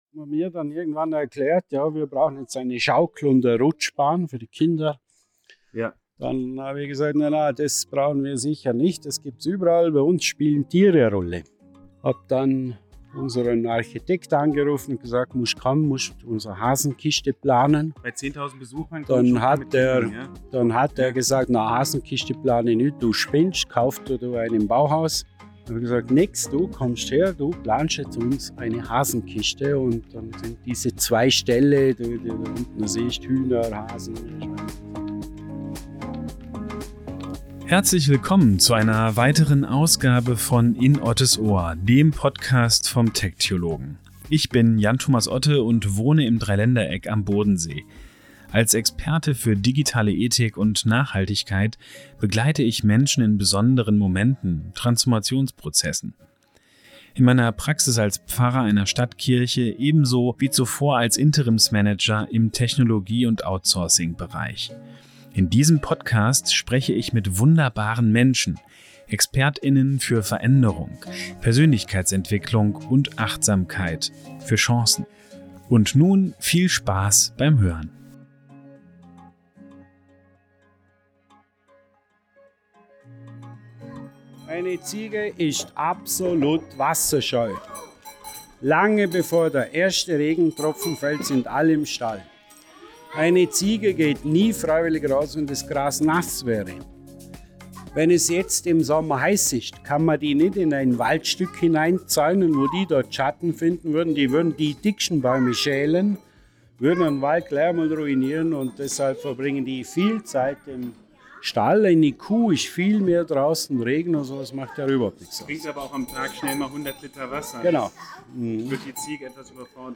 Ein Vorort-Termin in Vorarlberg.